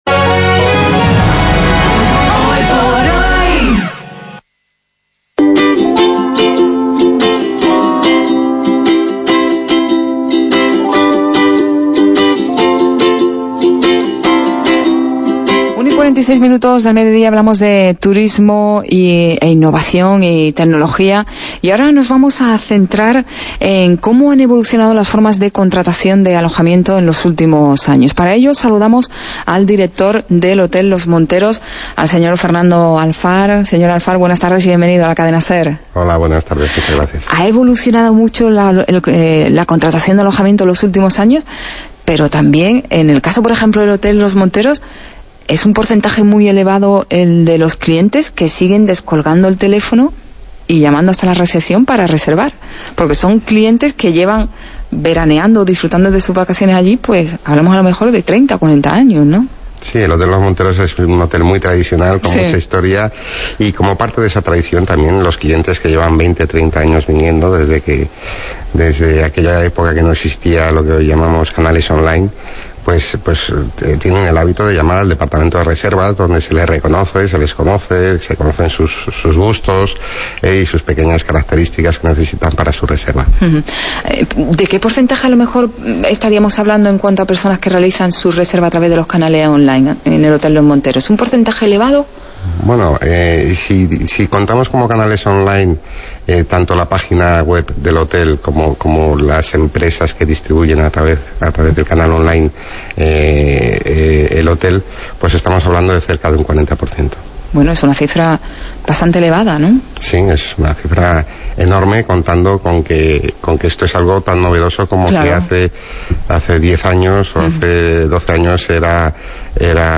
El pasado 16 de junio Andalucía Lab se convirtió en anfitriona de la Cadena Ser Marbella Costa del Sol  y su programa de radio Hoy por Hoy, donde se dieron cita emprendedores y empresas que apuestan por el uso de las nuevas tecnologías para mejorar su competitividad.
El Hotel Los Monteros, ubicado a pocos kilómetros del centro, fue la última entrevista realizada en el programa.